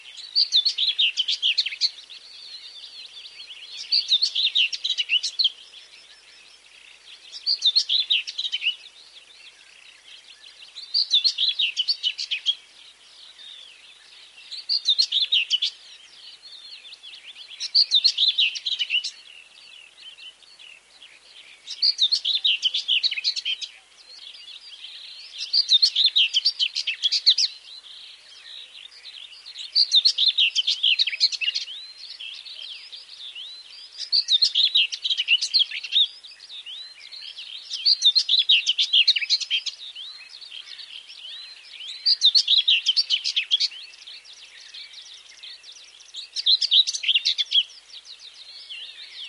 На этой странице собраны разнообразные звуки славки – от коротких позывов до продолжительных трелей.
Славки поют в лесу